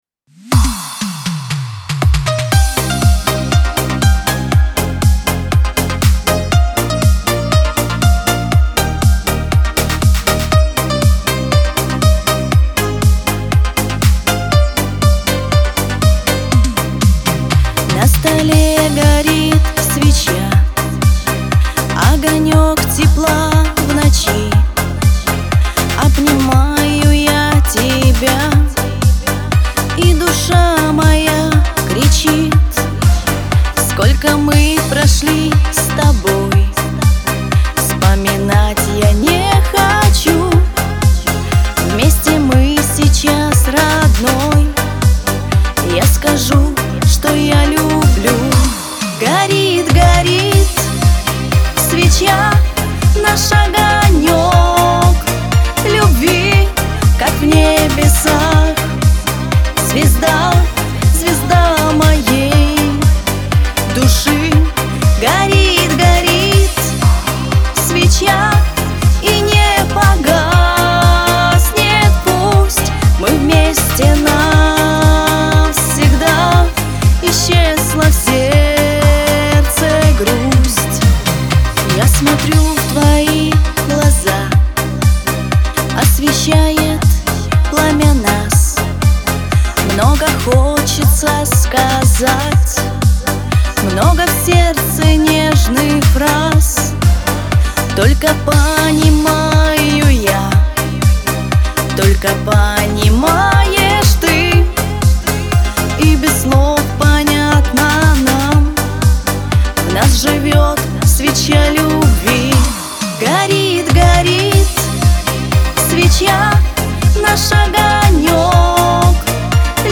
Шансон , грусть , Лирика